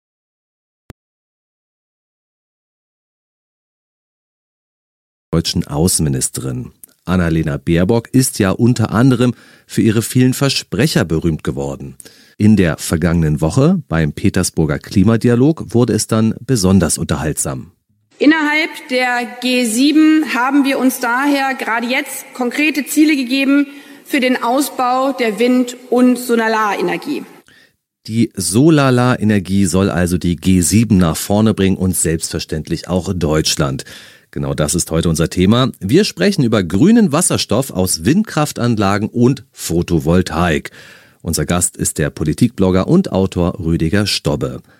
Das angekündigte Interview